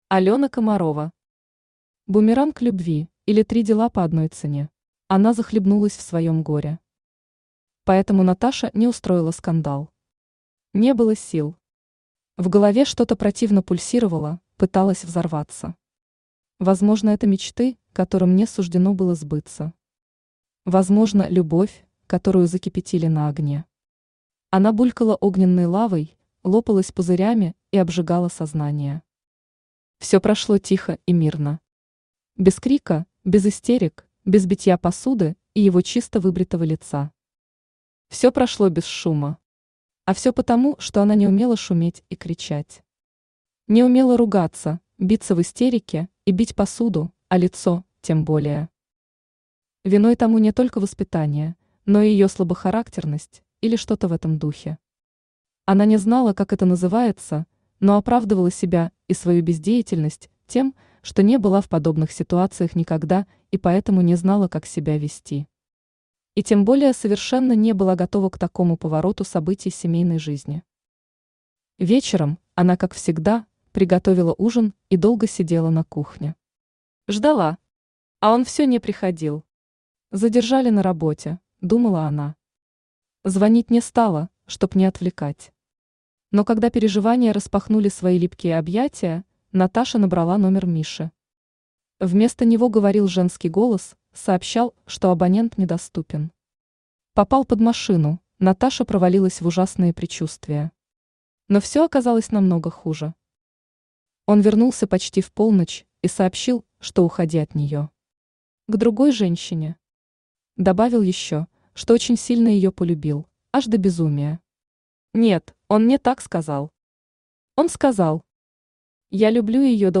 Аудиокнига Бумеранг любви, или Три дела по одной цене | Библиотека аудиокниг
Aудиокнига Бумеранг любви, или Три дела по одной цене Автор Алёна Александровна Комарова Читает аудиокнигу Авточтец ЛитРес.